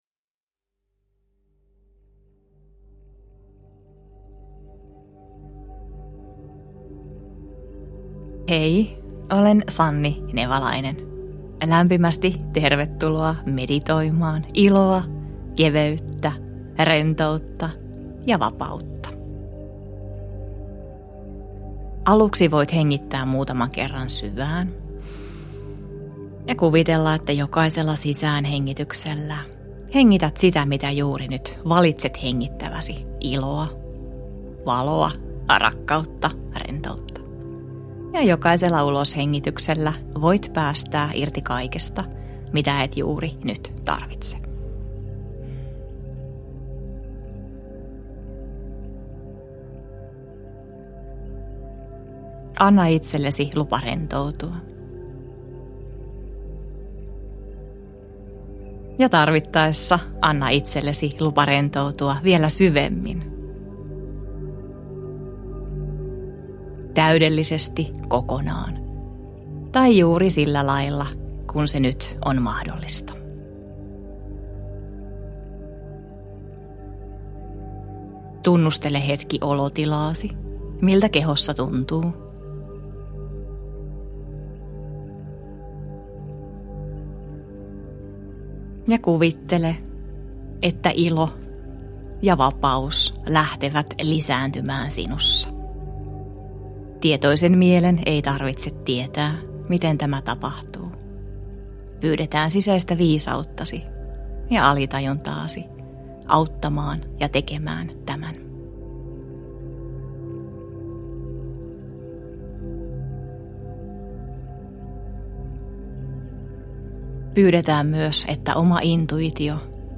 Ilon meditaatio – Ljudbok – Laddas ner
Äänite sisältää kaksi noin puolen tunnin meditaatiota: ensimmäinen osa rohkaisee sinua pysähtymään sisäisen ilosi äärelle, jossa uskallat olla vilpittömästi iloinen ja vapaa.